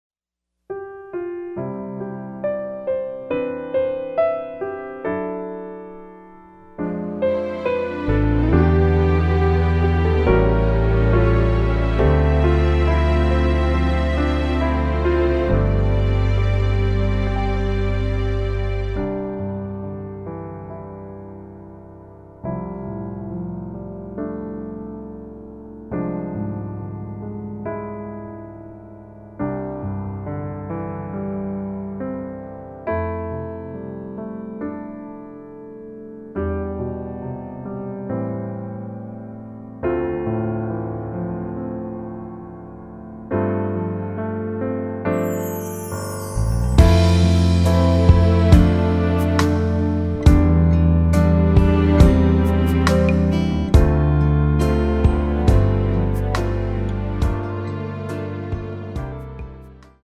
공식 음원 MR
앞부분30초, 뒷부분30초씩 편집해서 올려 드리고 있습니다.
중간에 음이 끈어지고 다시 나오는 이유는